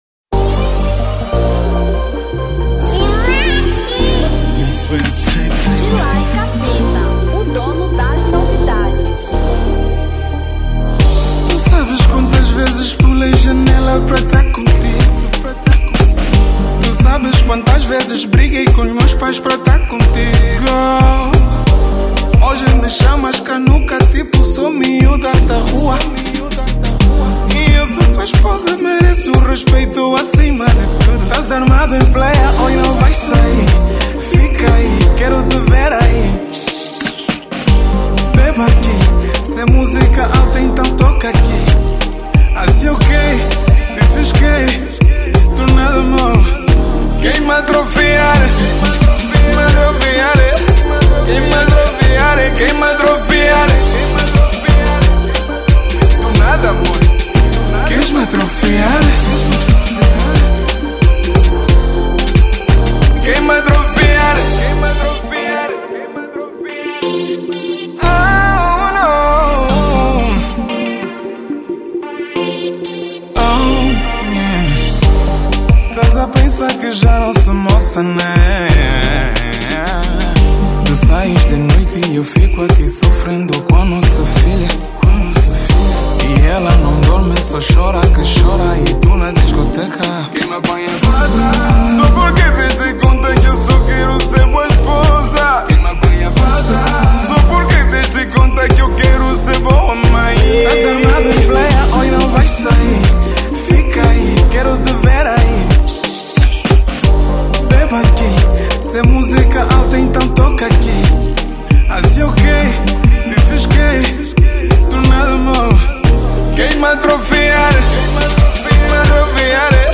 Zouk 2016